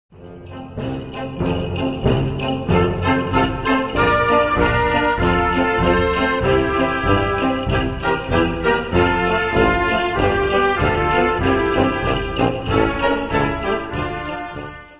Kategorie Blasorchester/HaFaBra
Unterkategorie Konzertmusik
Besetzung Flexi (variable Besetzung)
Besetzungsart/Infos 5part; Perc (Schlaginstrument)